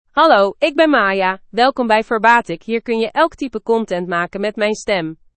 Maya — Female Dutch AI voice
Maya is a female AI voice for Dutch (Netherlands).
Voice sample
Listen to Maya's female Dutch voice.
Female
Maya delivers clear pronunciation with authentic Netherlands Dutch intonation, making your content sound professionally produced.